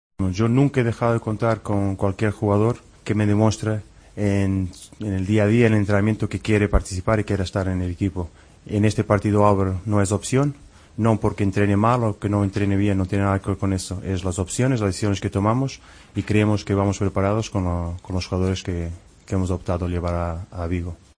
"Debemos transformar nuestras palabras y deseos en acciones. Hay que pelear y luchar más, dejar todo en el campo", dijo el técnico portugués, que anunció que Álvaro Negredo volvía a quedarse fuera de la citación contra el Celta en Vigo. "No es una opción", aseveró el portugués en rueda de prensa.